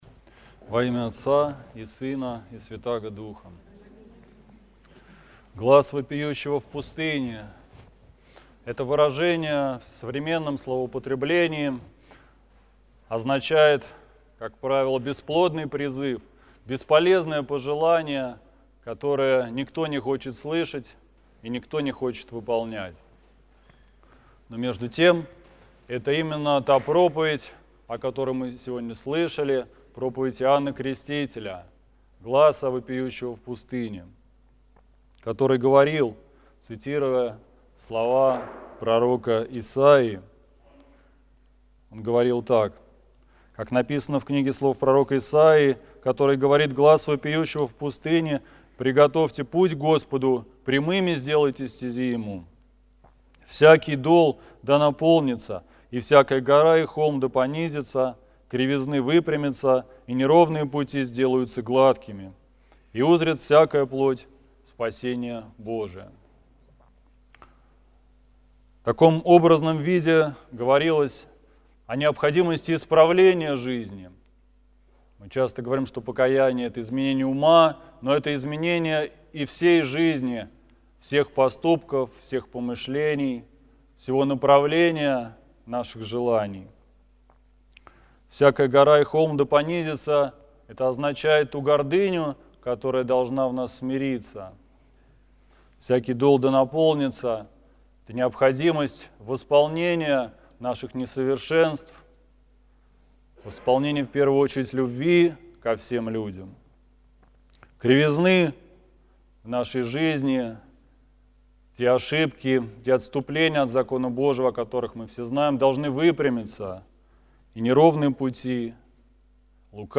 Слово в Крещенский сочельник
Псковская митрополия, Псково-Печерский монастырь
Проповедь короткая и такая ёмкая...